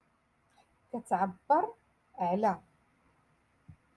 Moroccan Dialect-Rotation Six-Lesson Twenty One